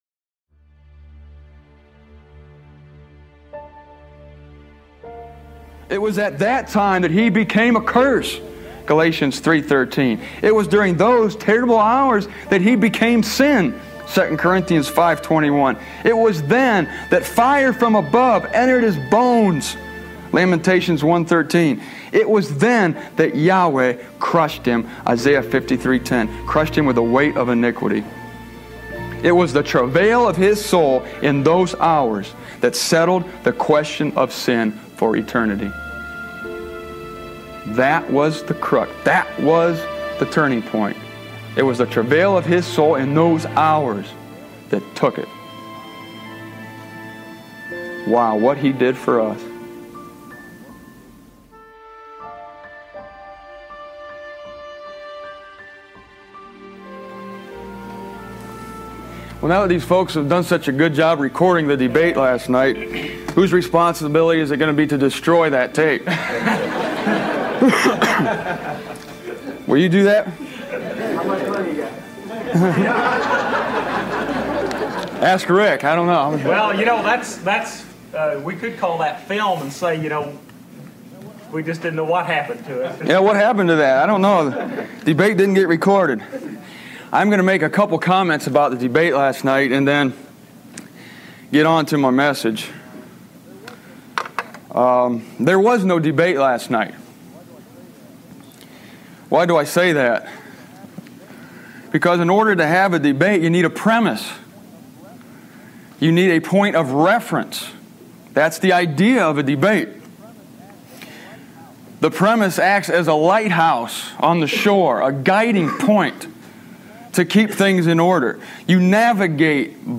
This is why the audio glitches in a couple places near the end; it's old.